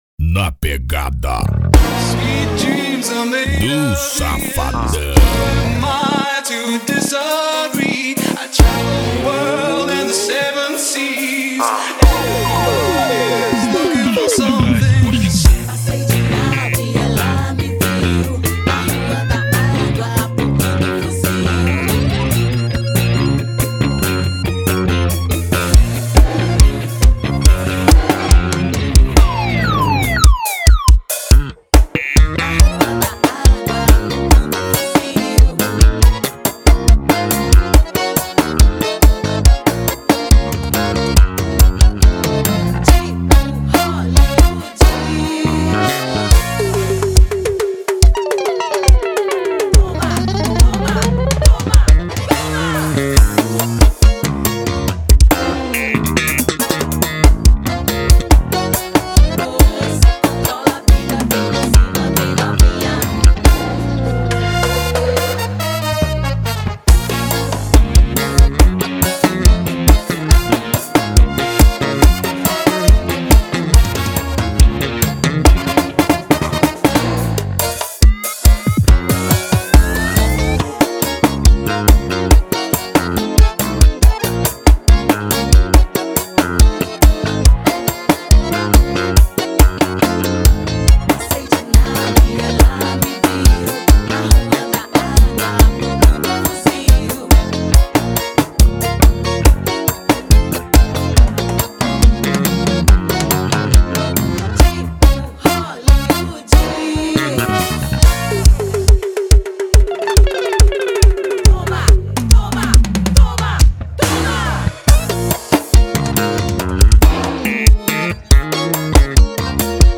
2024-02-14 22:40:16 Gênero: Forró Views